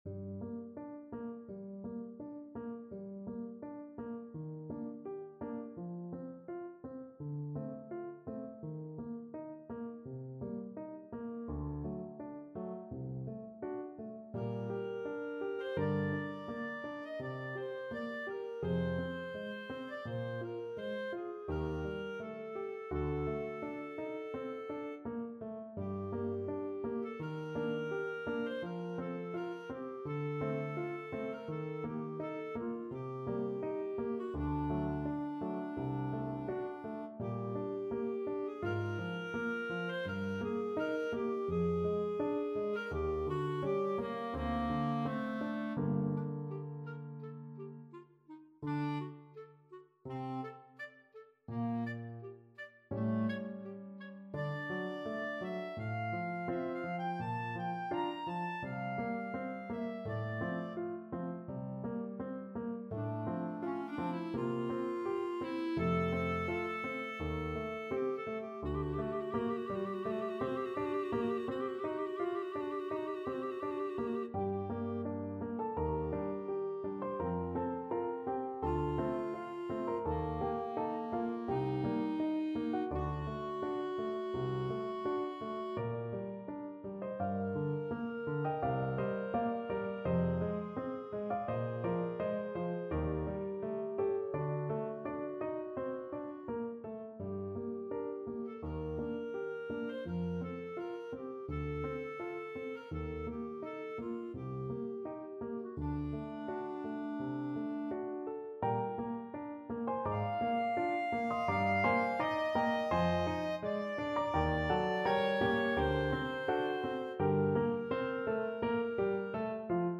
Clarinet 1 (Fm)Clarinet 2 (Fm)Piano (Fm)
G minor (Sounding Pitch) A minor (Clarinet in Bb) (View more G minor Music for Clarinet Duet )
4/4 (View more 4/4 Music)
Andante molto moderato (=84)
Clarinet Duet  (View more Intermediate Clarinet Duet Music)
Classical (View more Classical Clarinet Duet Music)